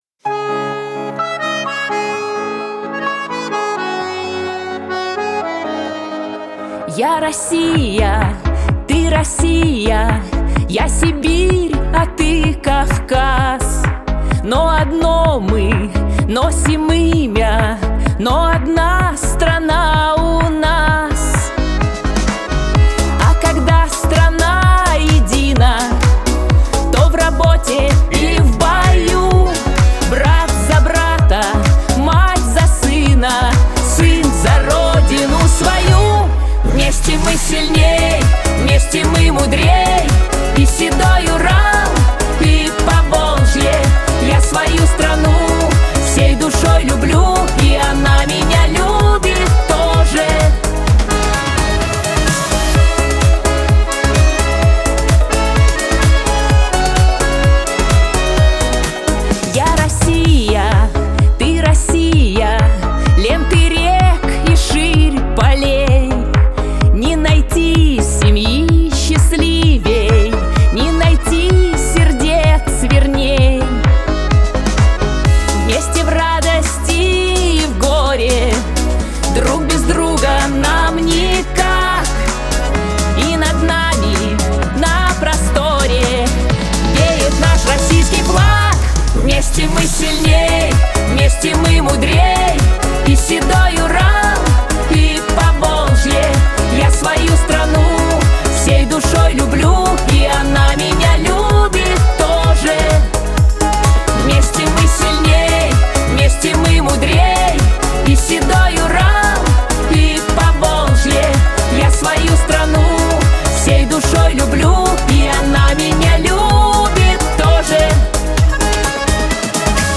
• Категория: Детские песни
патриотическая